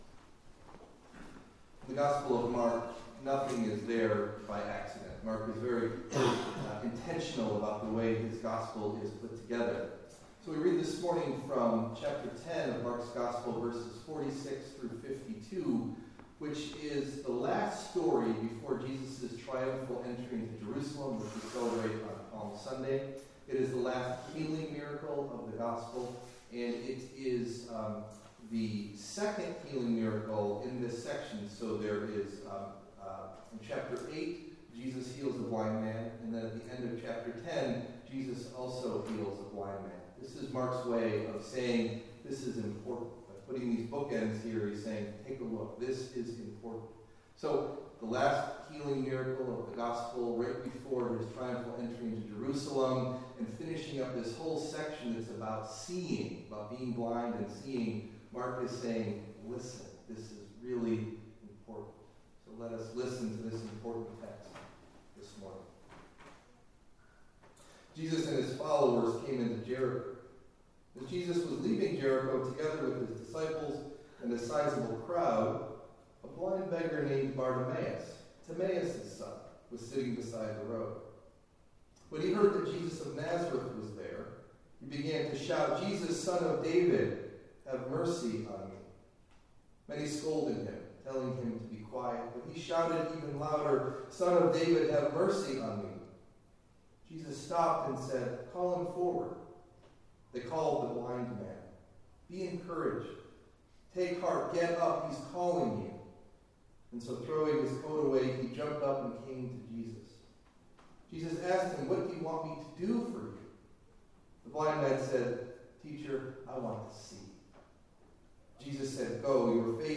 Sermon Delivered at: The United Church of Underhill (UCC and UMC)